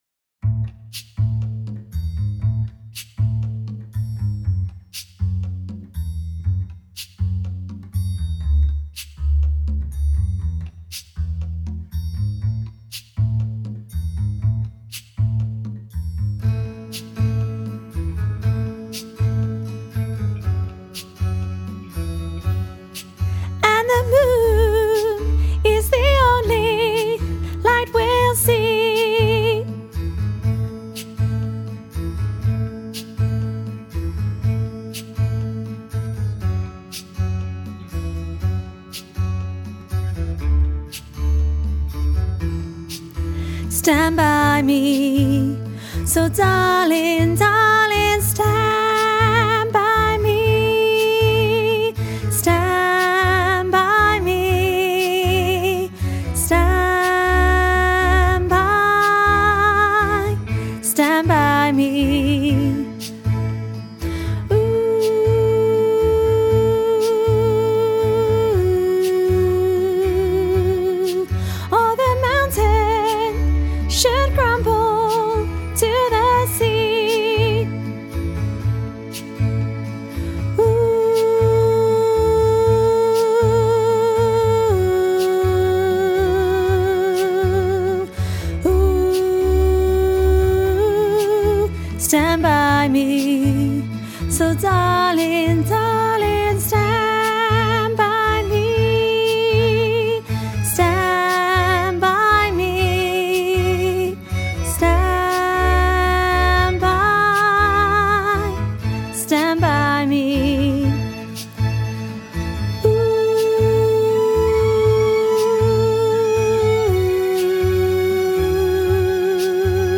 stand-by-me-soprano.mp3